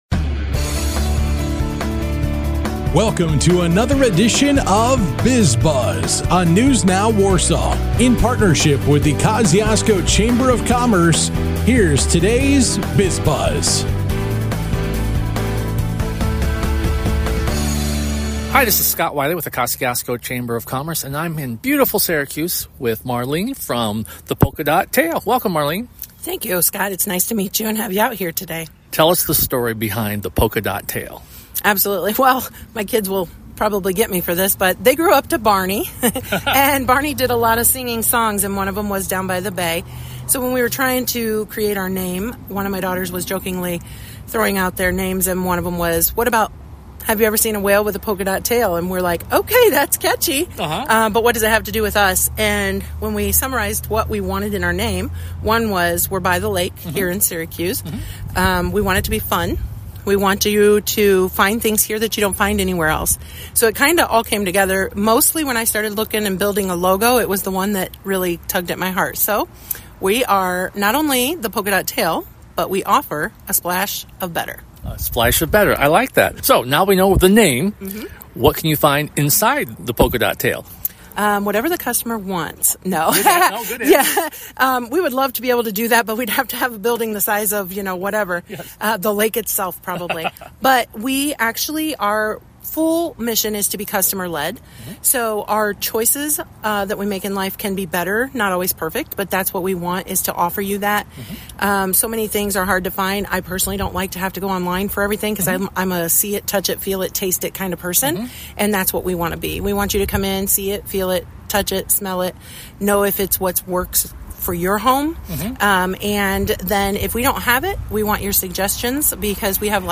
This week, a chat with Polka Dot Tail and EOS Systems